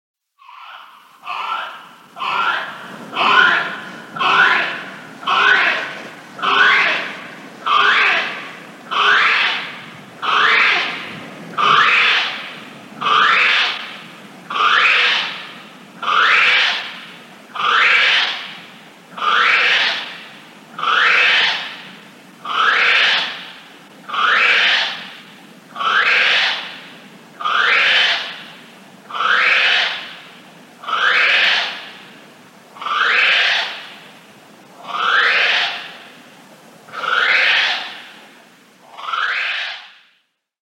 Identifying Kiwi Calls
The calls of the North Island brown kiwi are distinctive, full of character, and loud!
call_kiwi_female.mp3